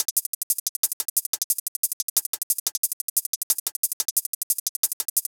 • 12 HH Loops: Enhance your beats with high-quality hi-hat loops that bring crispness and precision to your drum patterns.